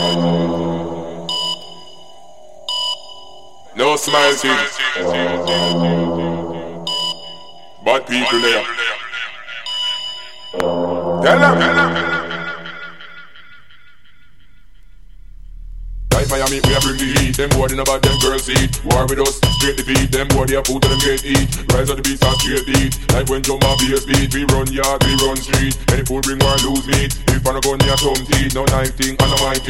TOP >Vinyl >Grime/Dub-Step/HipHop/Juke
TOP > Vocal Track